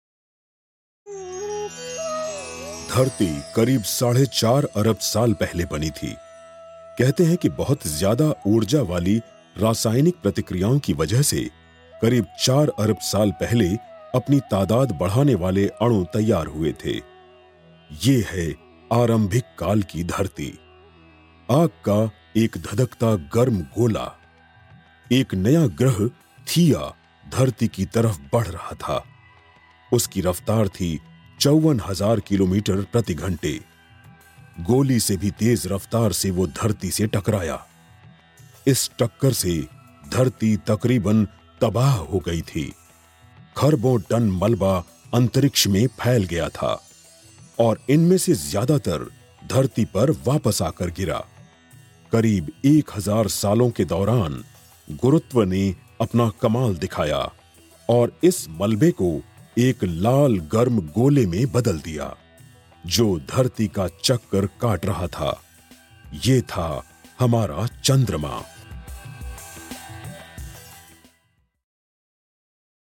商业广告【浑厚大气】